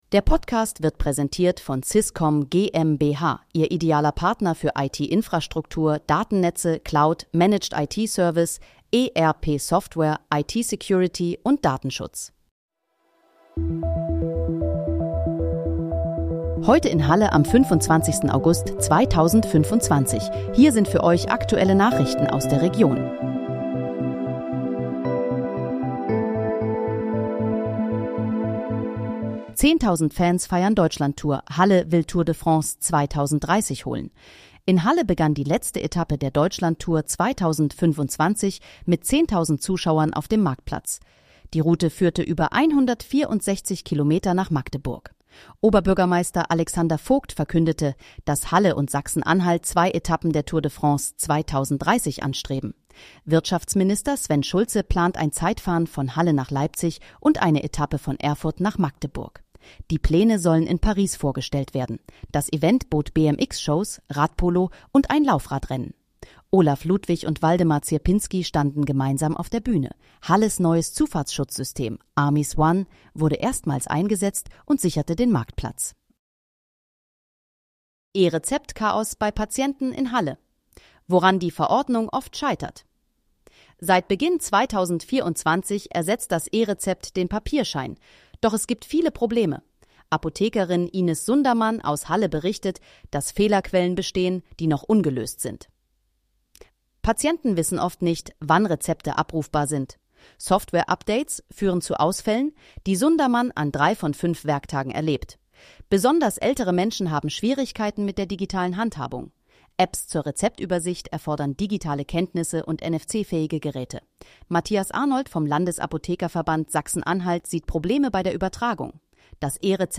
Heute in, Halle: Aktuelle Nachrichten vom 25.08.2025, erstellt mit KI-Unterstützung
Nachrichten